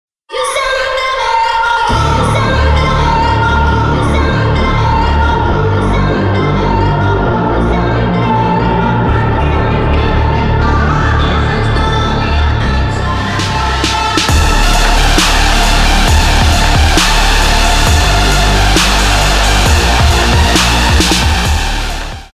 Bass & Lead
Здравствуйте.кто может помочь накрутить такой басс и лид?Пробовал сам крутить басс,но после лп фильтра не было такого рычания как тут: